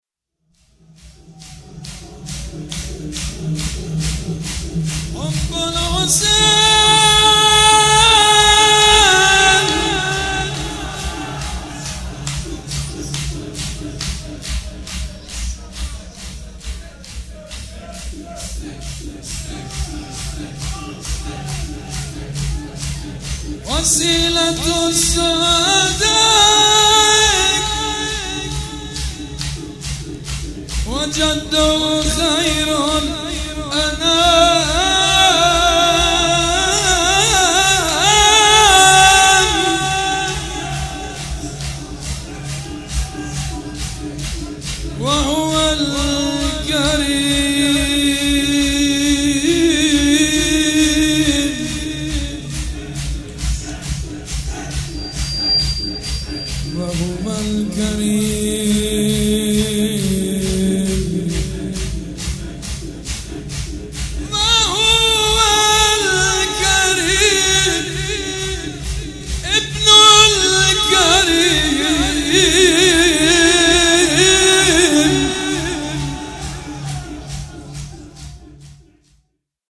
شب یازدهم محرم الحرام‌
شور
حاج سید مجید بنی فاطمه
مراسم عزاداری شب شام غریبان